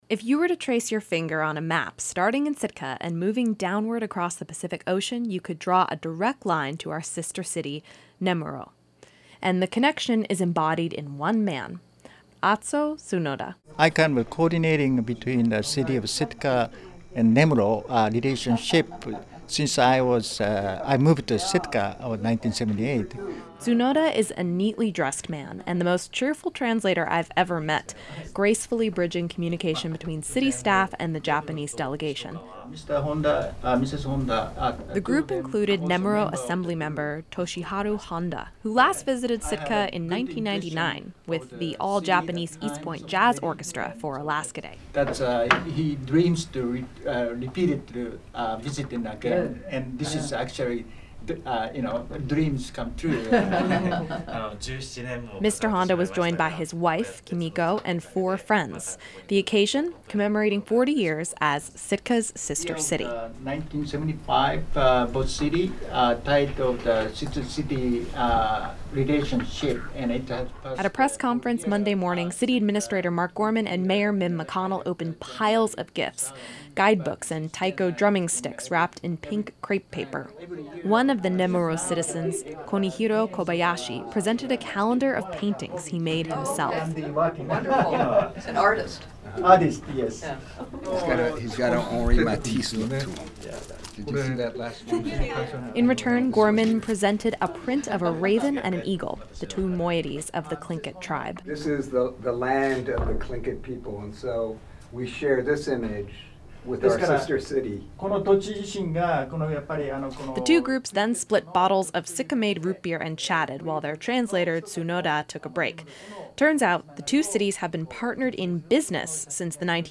Group: (Laughs)